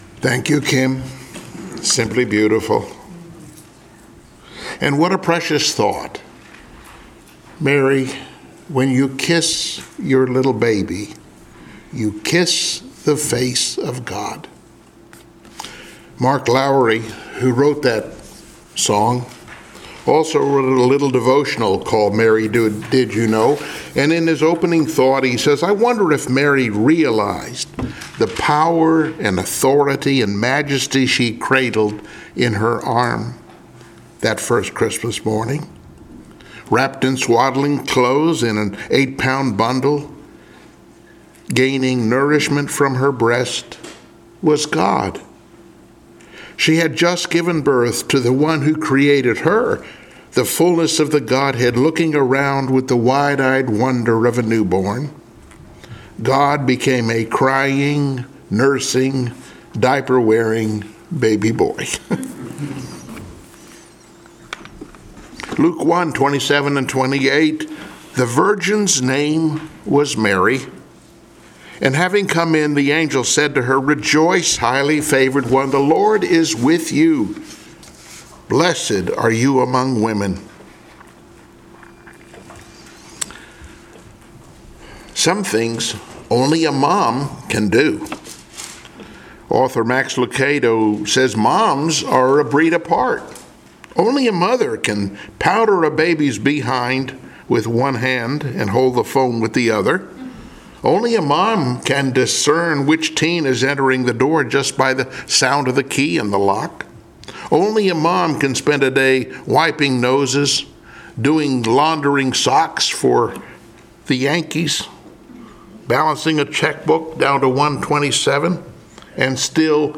Luke 1:27-28 Service Type: Sunday Morning Worship Download Files Notes Bulletin « “Cast of Characters